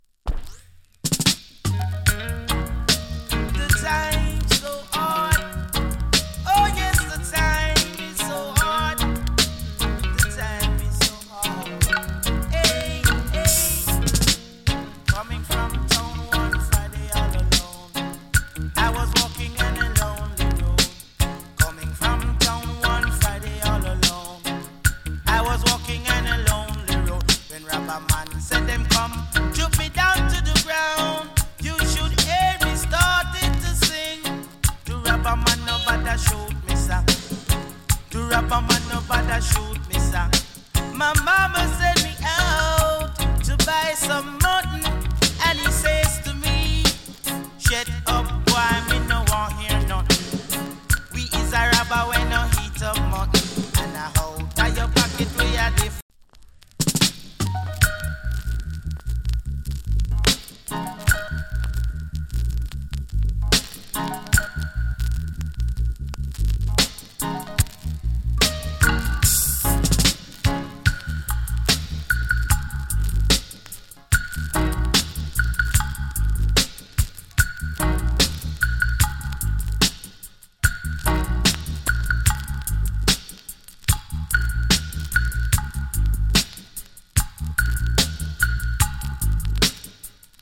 チリ、パチノイズわずかに有り。
KILLER EARLY DANCE HALL !